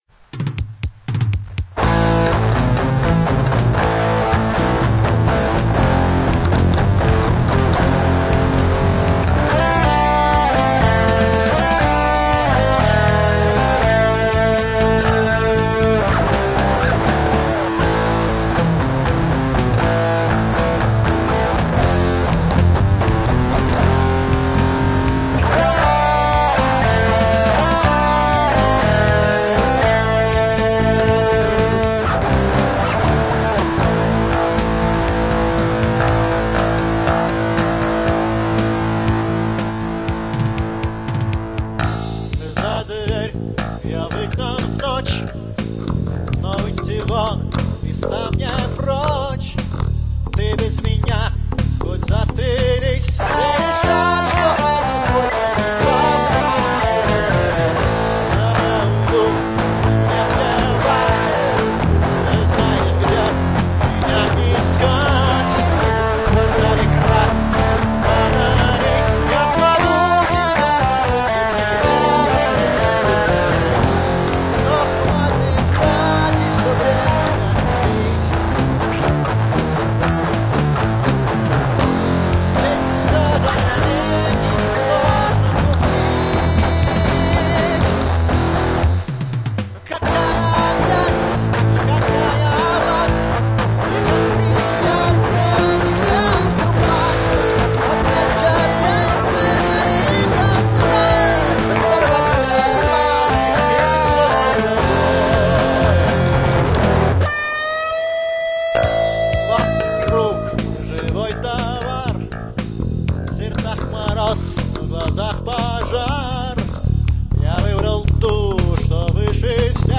Наша первая запись. Перепевка